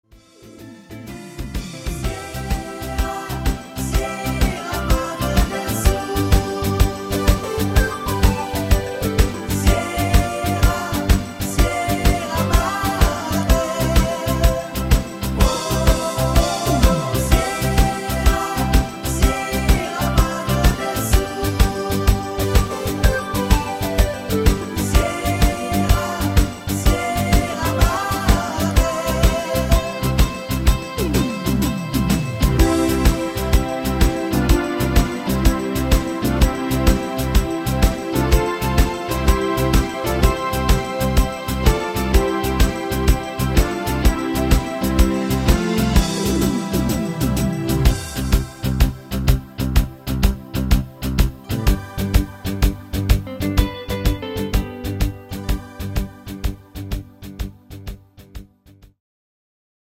im Discofox-Partysound